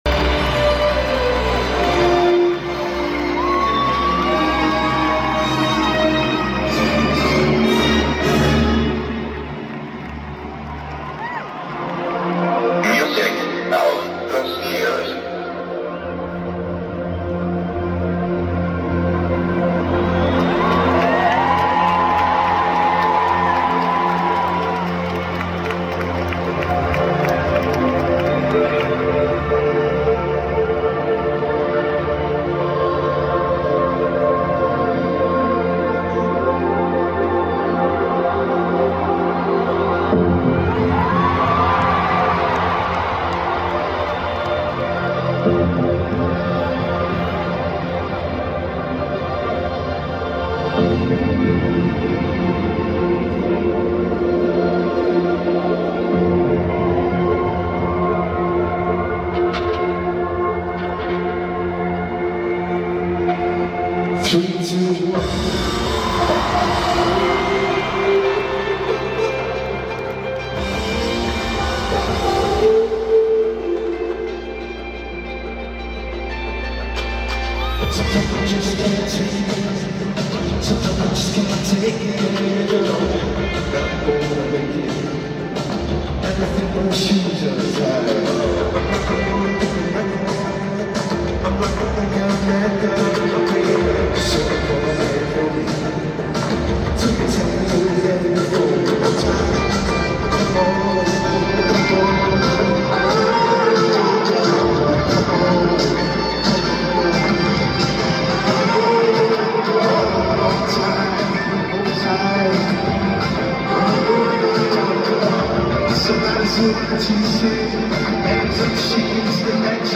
Wembley Stadium